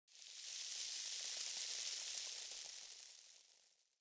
دانلود آهنگ بیابان 8 از افکت صوتی طبیعت و محیط
دانلود صدای بیابان 8 از ساعد نیوز با لینک مستقیم و کیفیت بالا
جلوه های صوتی